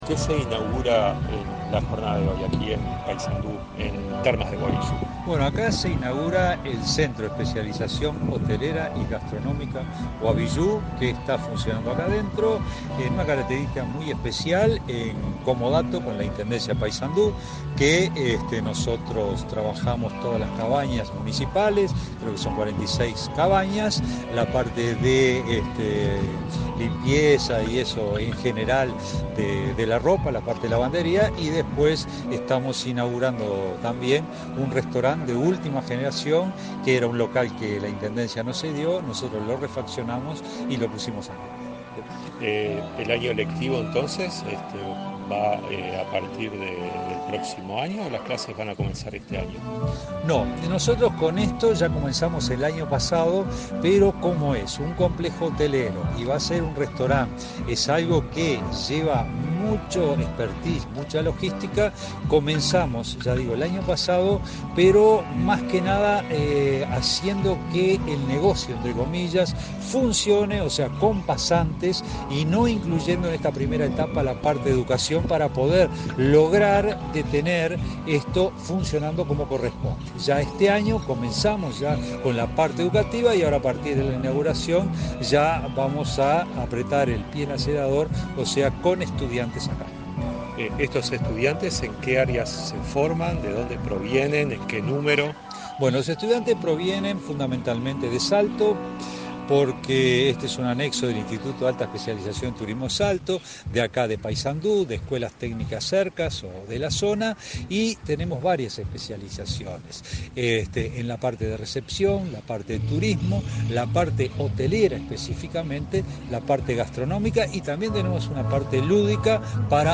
Entrevista al director general de Educación Técnico Profesional, Juan Pereyra
La Administración Nacional de Educación Pública (ANEP) y la Dirección General de Educación Técnico Profesional (DGETP-UTU) inauguraron, este 24 de junio, el Centro de Especialización Hotelera, ubicado en Termas de Guaviyú, en el departmaento de Paysandú. En la oportunidad, el director de la UTU, Juan Pereyra, realizó declaraciones a Comunicación Presidencial.